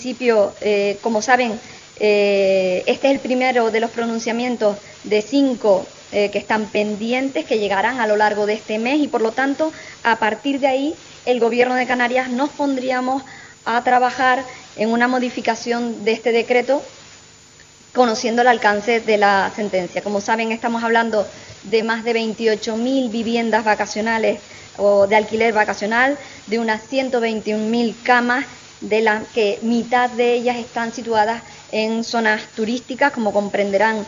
La portavoz y consejera de Hacienda, Rosa Dávila, afirma en COPE Canarias que aún es "precipitado" analizar la sentencia del Tribunal Superior de Justicia de Canarias que anula el núcleo central del decreto de alquiler, si bien precisa que el Ejecutivo va a esperar por los cuatro pronunciamientos judiciales que quedan pendientes para modificar el decreto